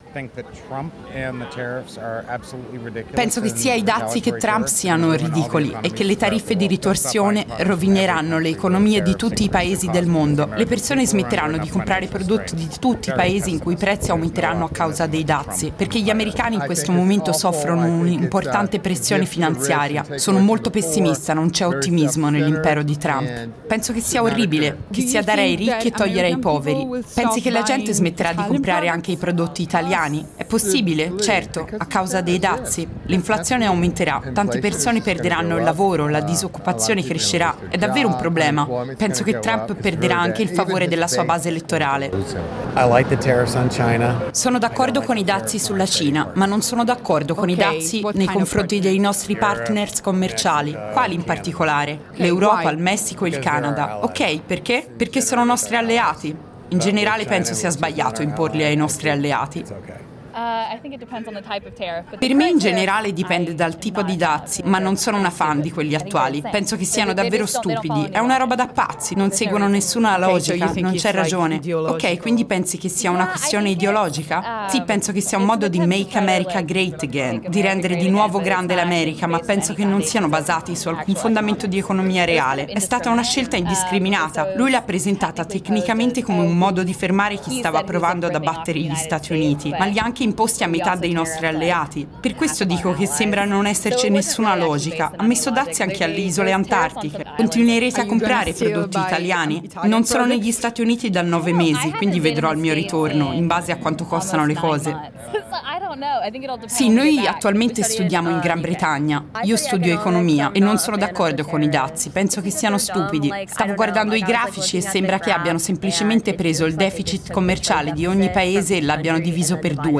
Cosa pensano i turisti americani dei dazi? Interviste in centro a Firenze